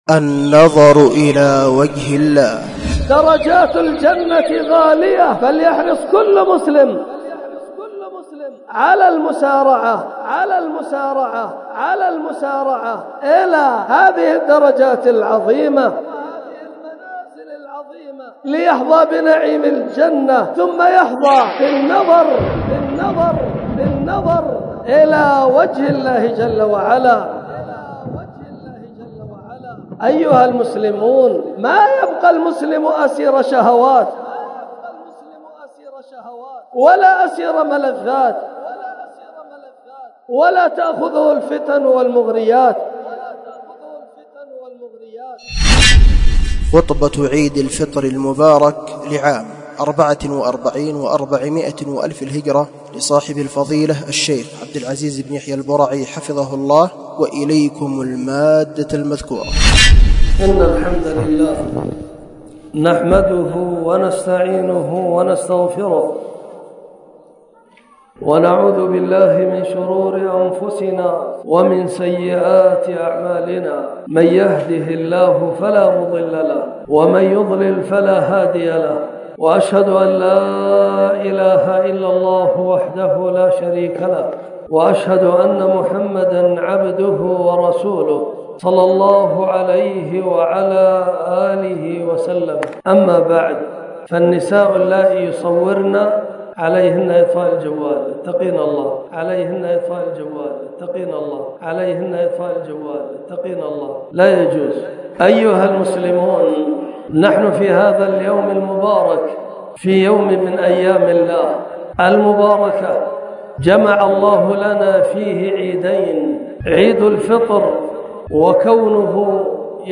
النظر إلى وجه الله عزوجل – خطبة عيد الفطر 1444